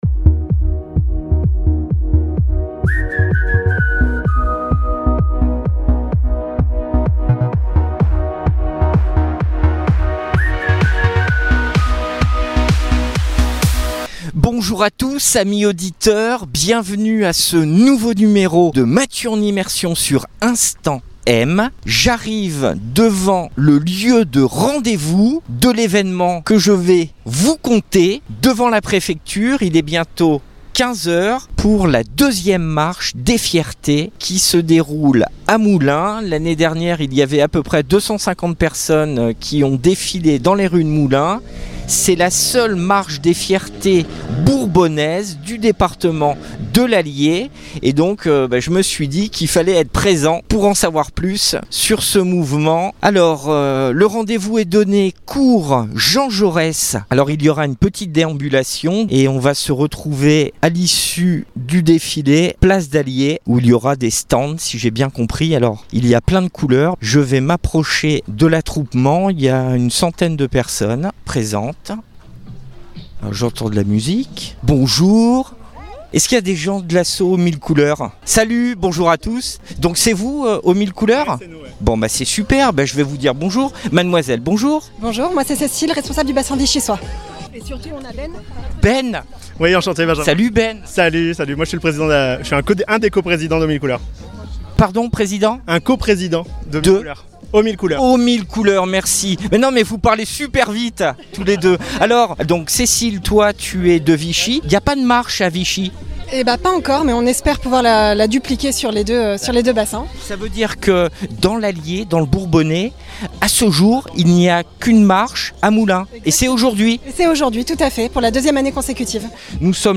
Voici un aperçu radiophonique de la 2ème Marche des Fiertés, qui s'est déroulée à Moulins le 14 juin dernier en centre ville !